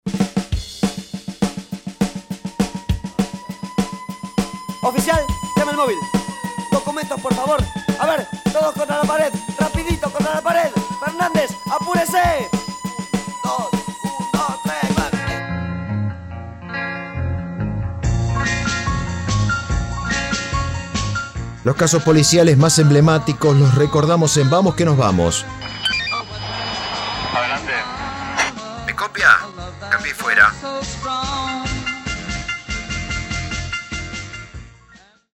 ARTÍSTICA POLICIALES
DEMO-ARTISTICA-POLICIALES.mp3